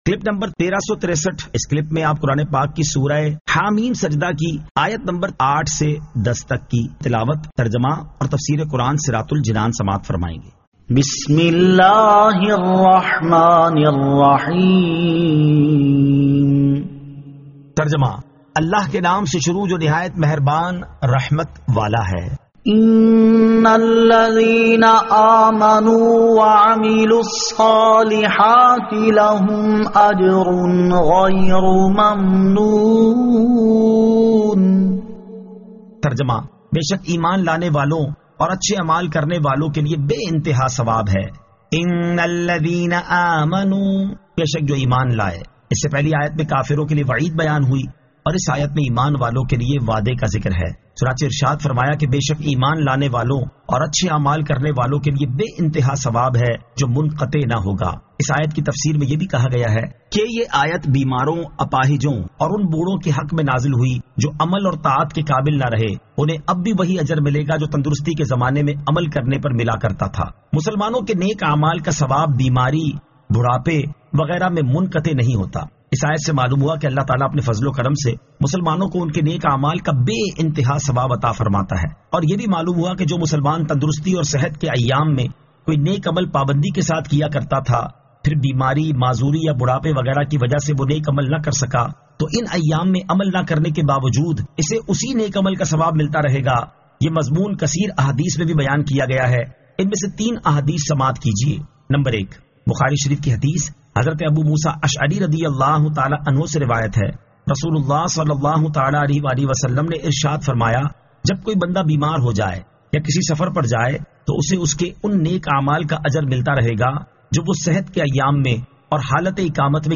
Surah Ha-Meem As-Sajdah 08 To 10 Tilawat , Tarjama , Tafseer